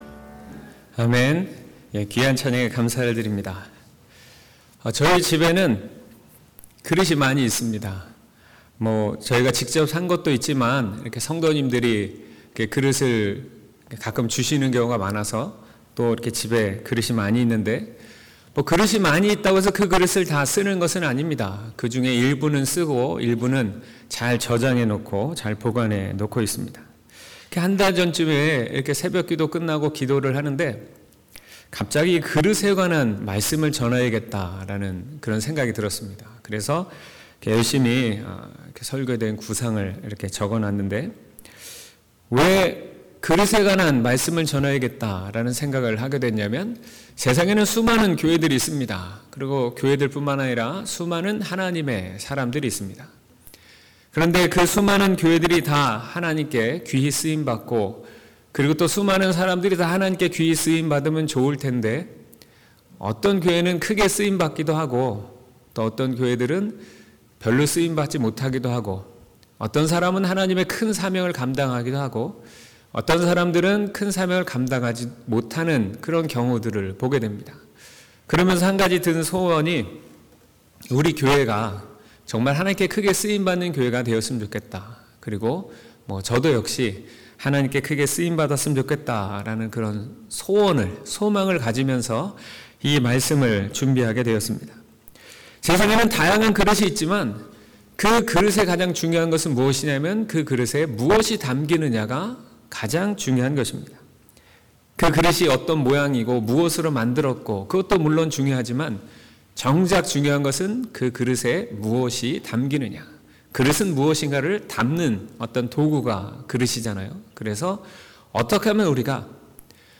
1월 13일 주일 설교 /그릇을 준비하라/딤후2:20-21